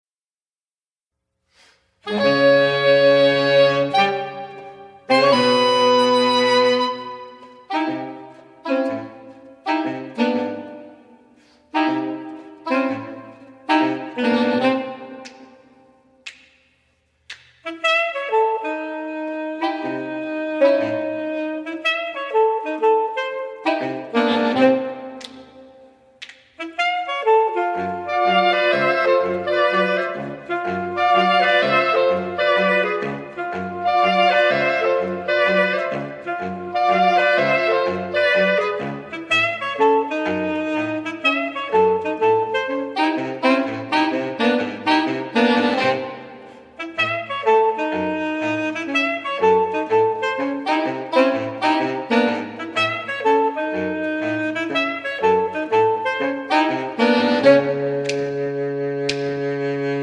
live recordings
baritone saxophone
tenor saxophone
alto saxophone
soprano and alto saxophone